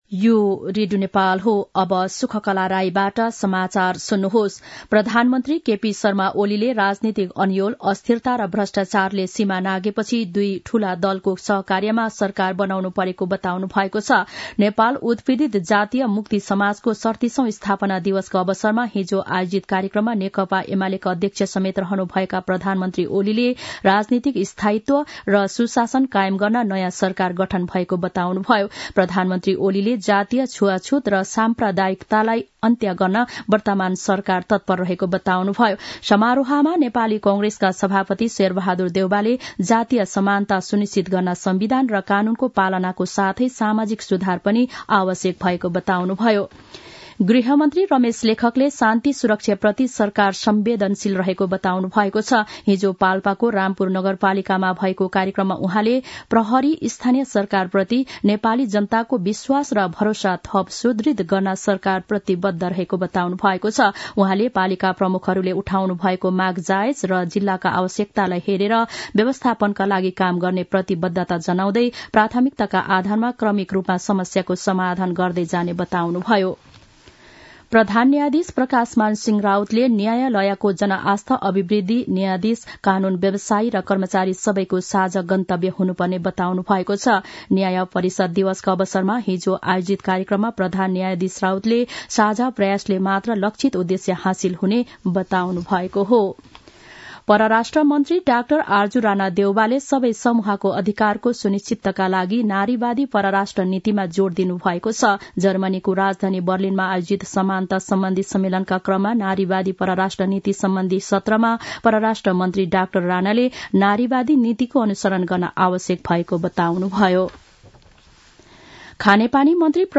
मध्यान्ह १२ बजेको नेपाली समाचार : ५ पुष , २०८१
12-am-nepali-news-1-14.mp3